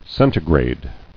[cen·ti·grade]